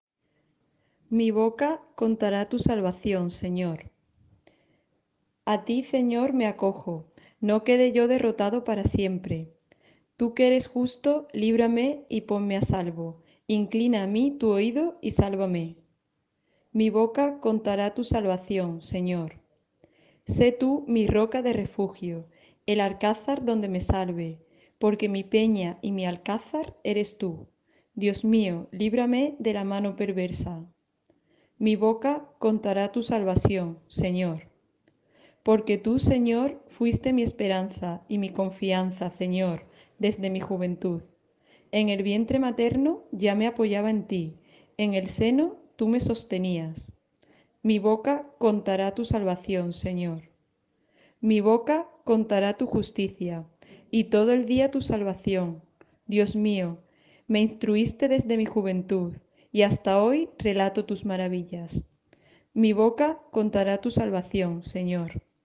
Salmo
Salmo-Martes-Santo.m4a